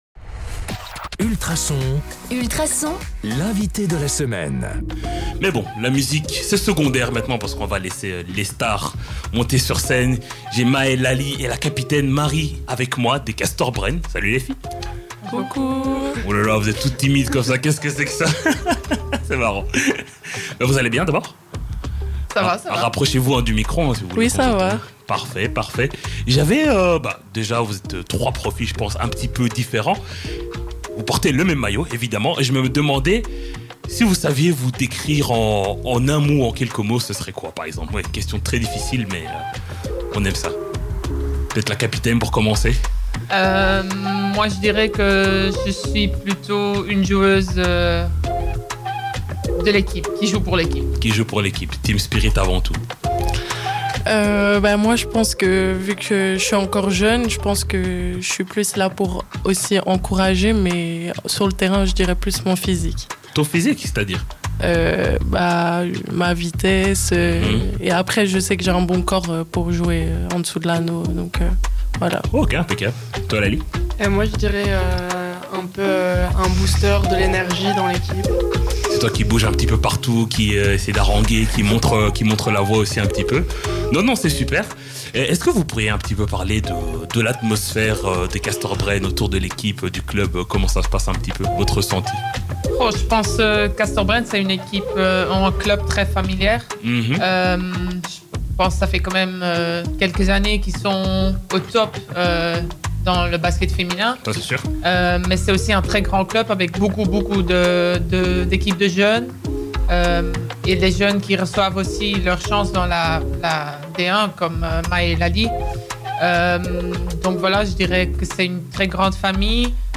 Ultrason a reçu dans son studio 3 joueuses